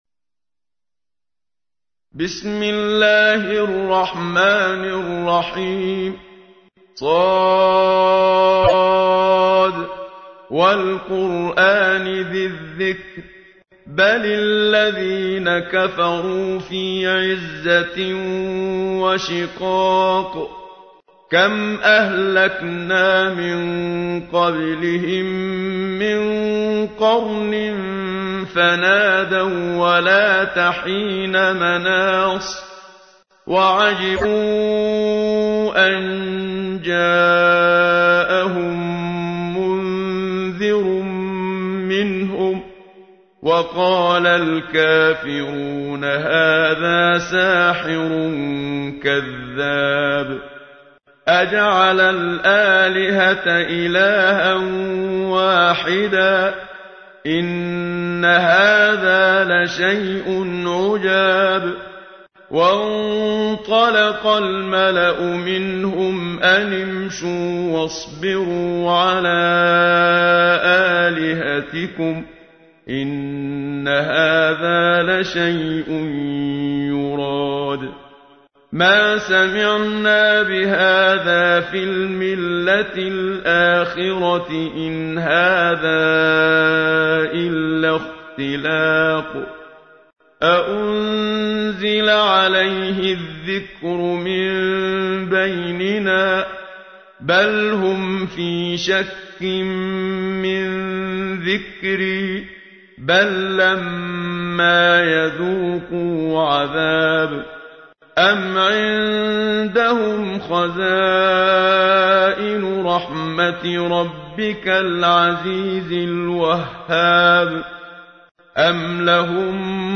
تحميل : 38. سورة ص / القارئ محمد صديق المنشاوي / القرآن الكريم / موقع يا حسين